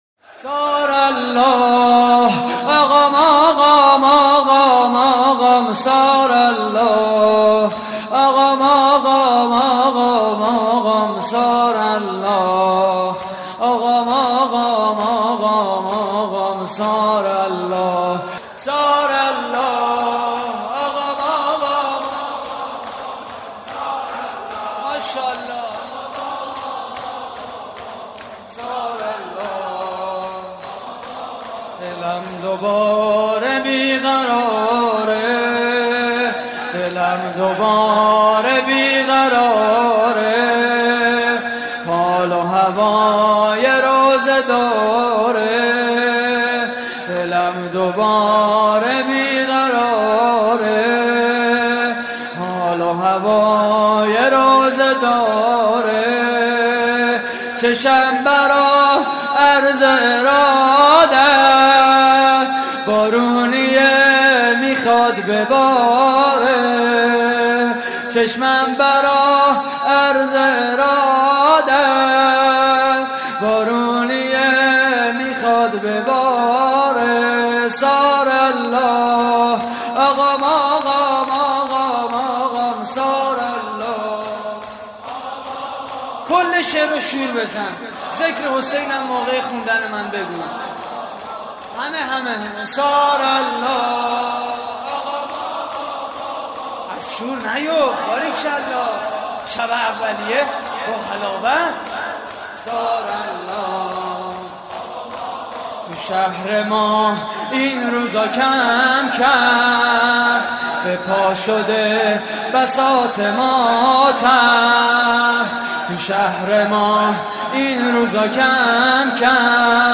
شور: ثارالله آقام آقام آقام آقام
مراسم عزاداری شب اول ماه محرم